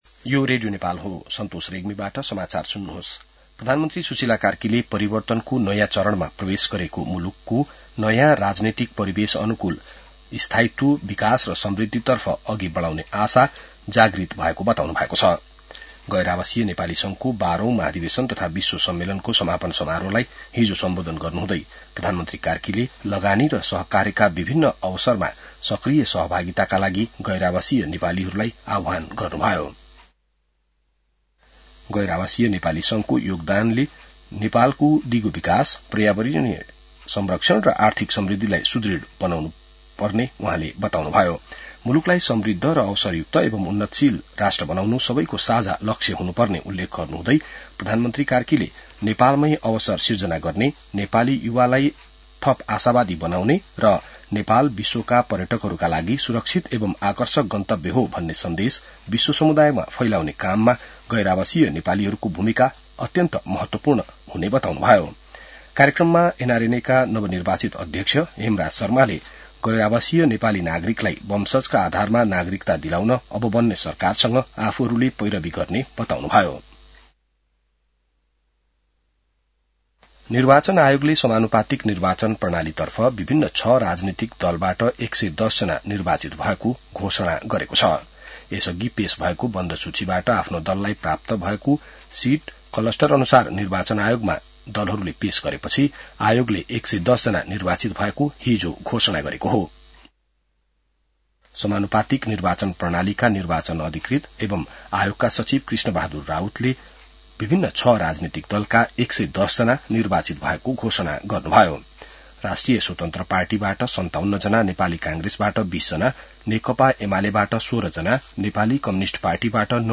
बिहान ६ बजेको नेपाली समाचार : ३ चैत , २०८२